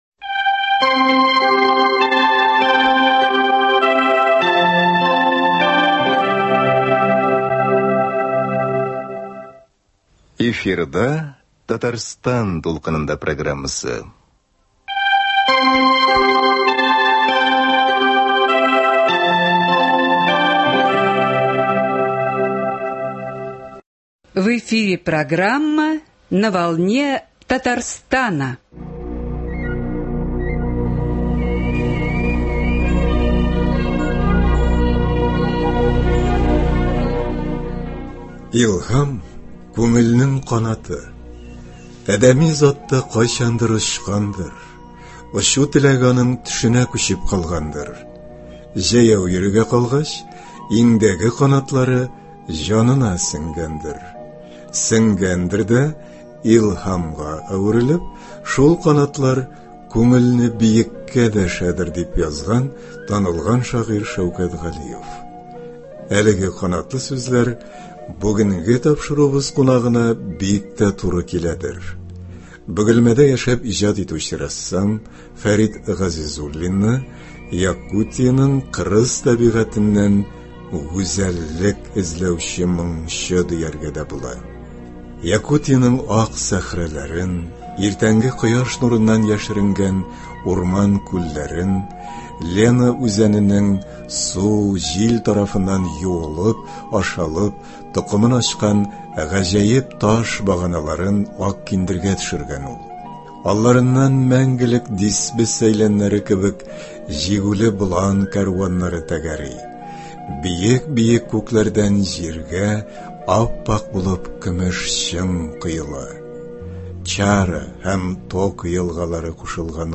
Әңгәмә барышында сез рәссамның башка юнәлештәге кызыксынулары турында да ишетерсез.